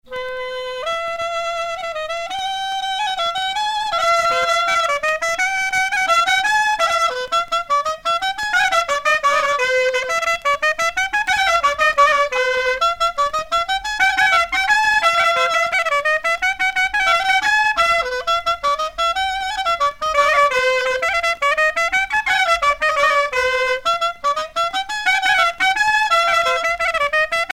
Mémoires et Patrimoines vivants - RaddO est une base de données d'archives iconographiques et sonores.
danse : fisel (bretagne)
Pièce musicale éditée